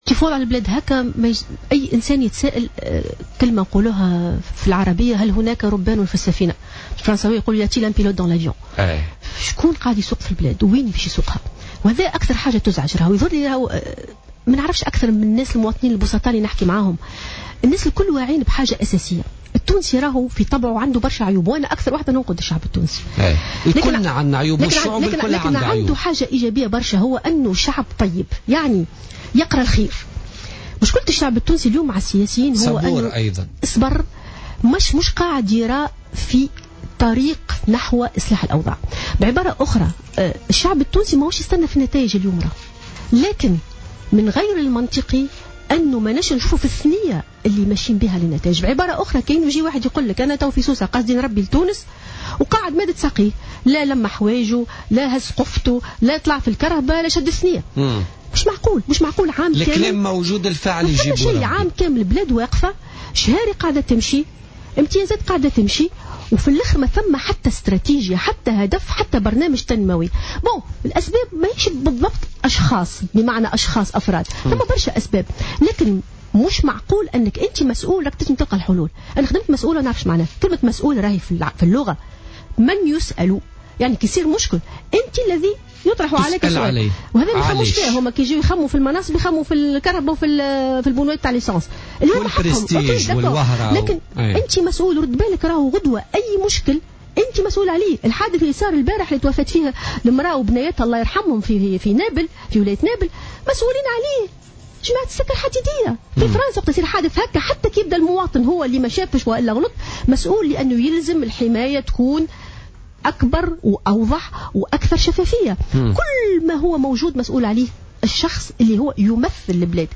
وقالت في مداخلة لها اليوم في برنامج "بوليتيكا" إن الحكومات المتعاقبة منذ الثورة لم تقدّم أي إضافة، مشيرة إلى إن الإشكال لا يتعلّق بالأشخاص بل بغياب رؤية واستراتيجيات واضحة.